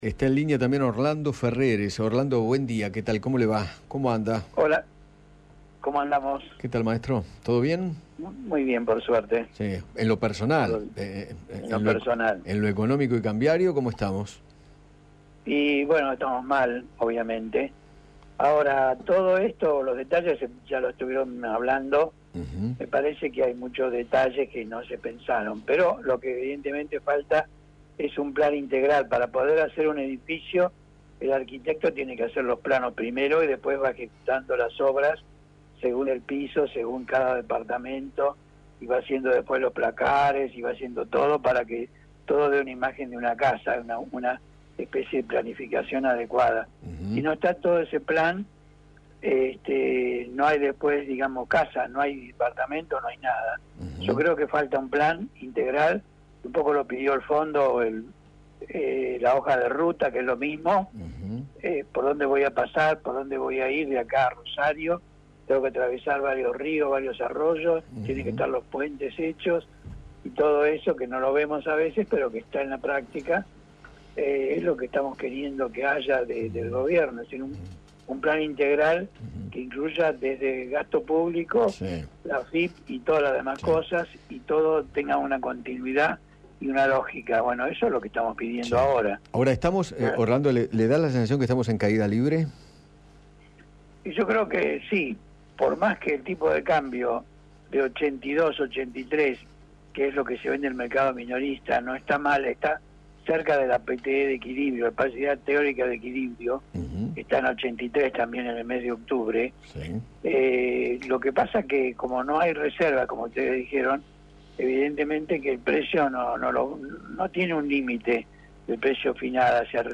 El economista Orlando Ferreres dialogó con Eduardo Feinmann sobre la alta cotización del dólar blue, la situación económica actual y consideró que “estamos muy cerca del iceberg; no sé si Alberto Fernández podrá cambiar el rumbo para que no choquemos”.